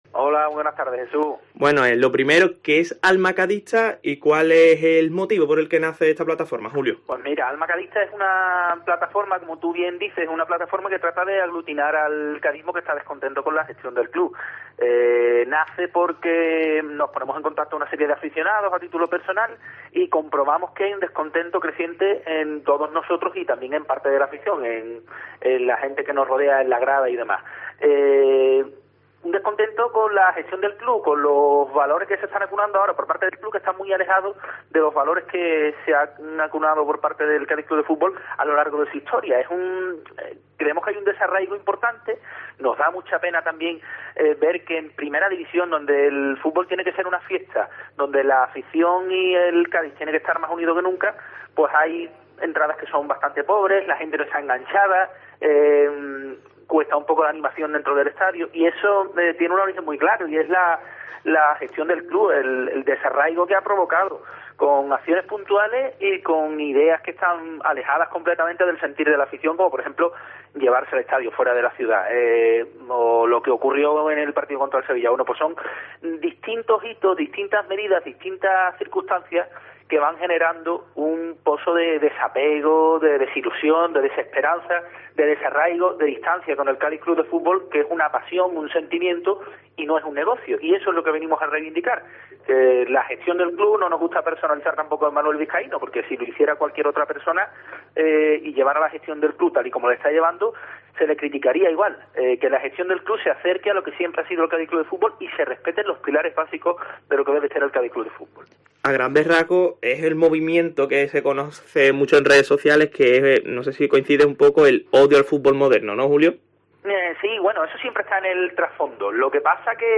ha hablado en DEPORTES COPE CÁDIZ sobre esta iniciativa